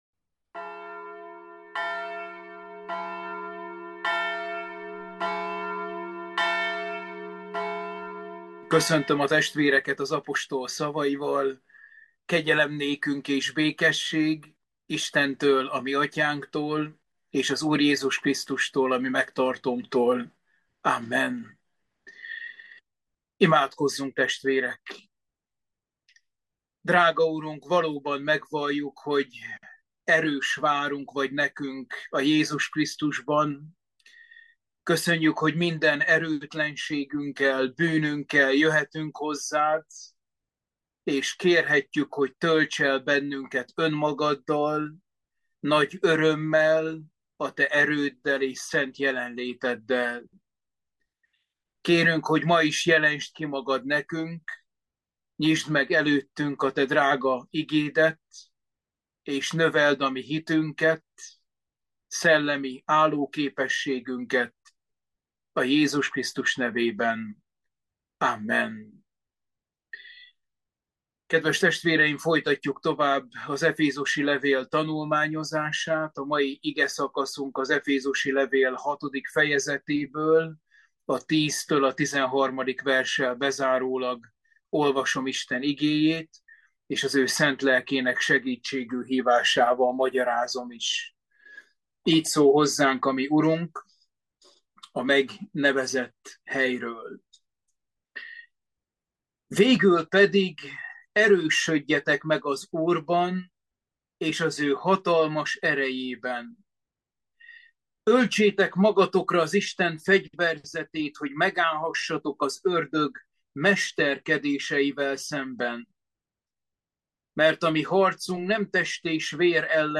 Efézusi levél – Bibliaóra 24